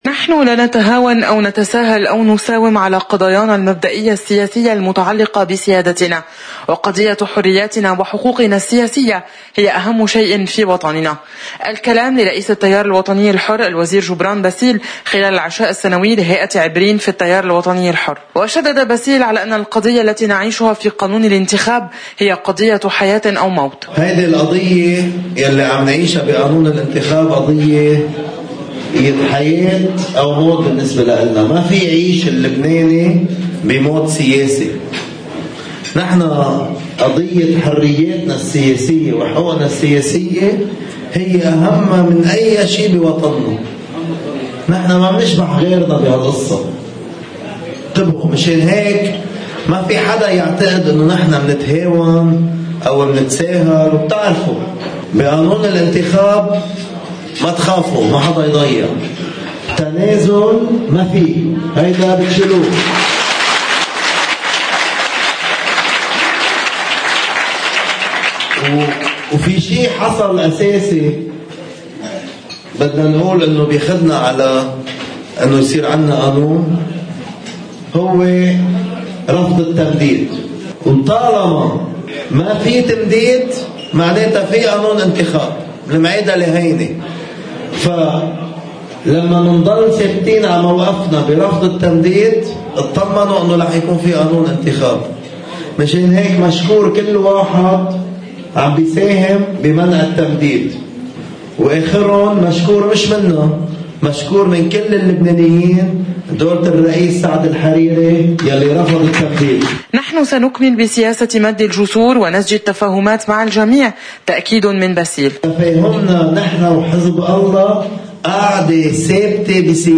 مقتطف من حديث رئيس التيار الوطني الحر جبران باسيل في حفل العشاء السنوي لهيئة عبرين في التيار: